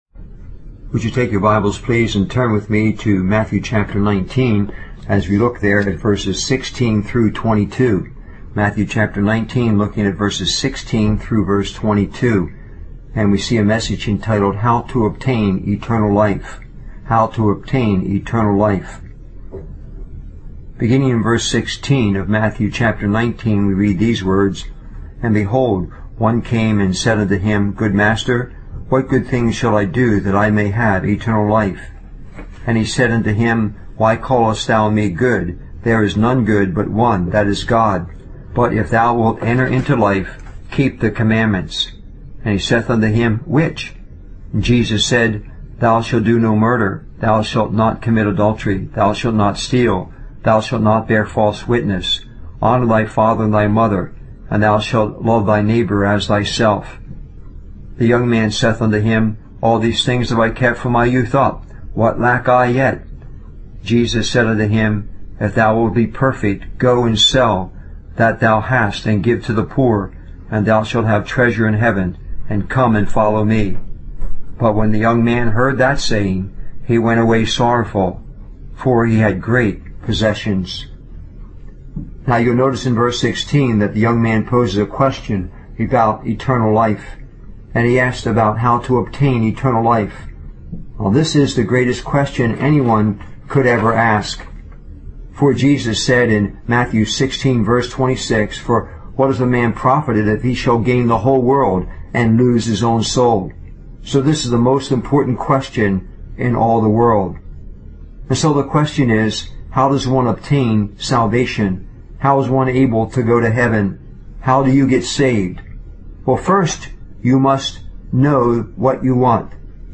Reading from the Bible King James Version Matthew 19: 16-22